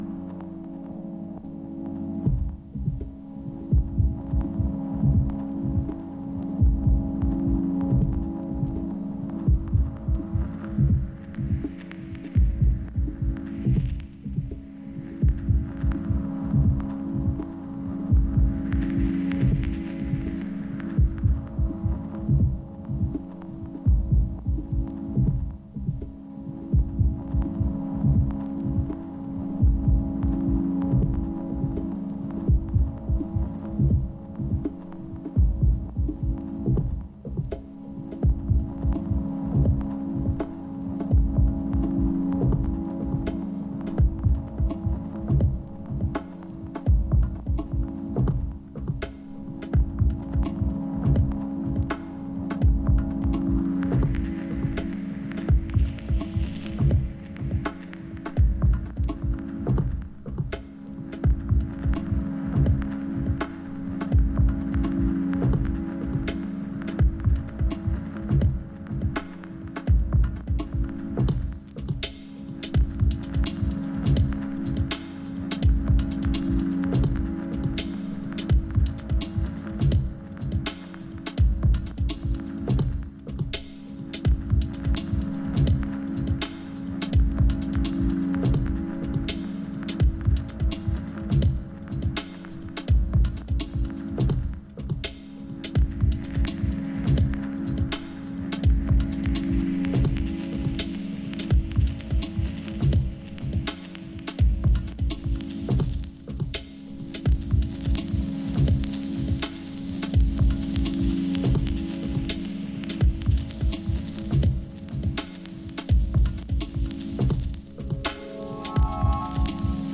Ambient IDM Minimal